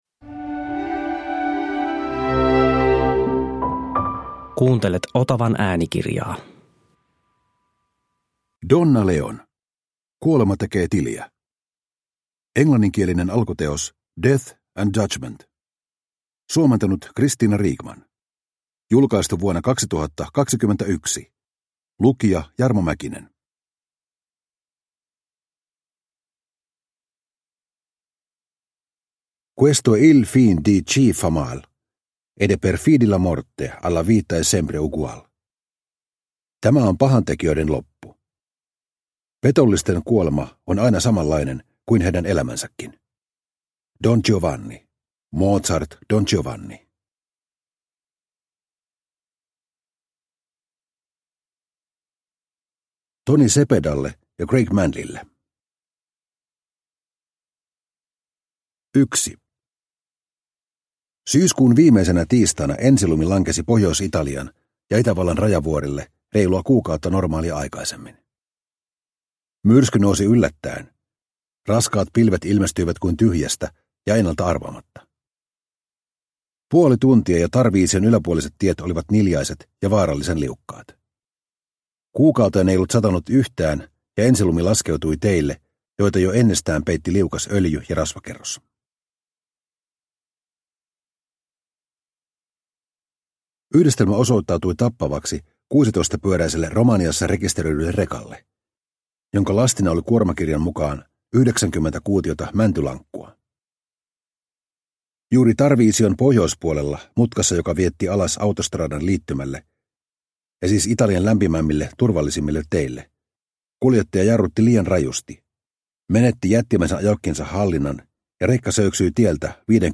Kuolema tekee tiliä – Ljudbok – Laddas ner